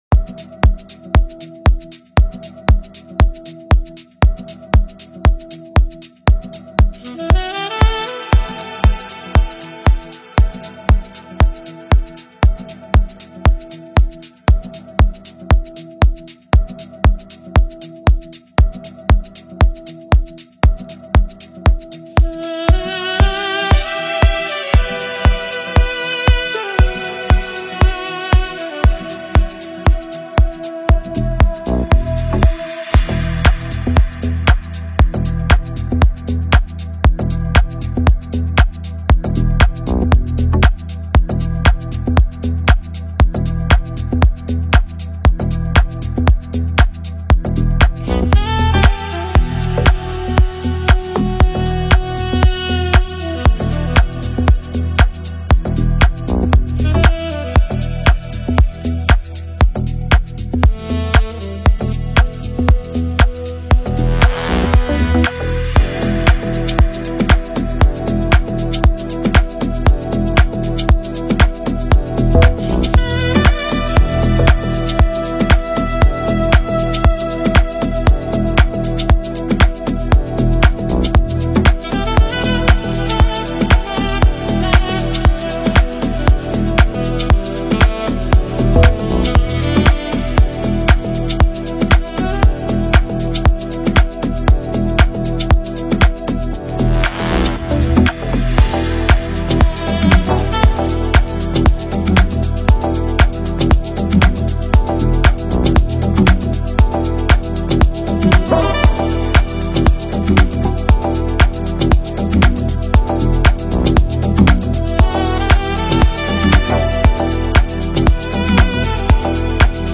smooth production, catchy rhythm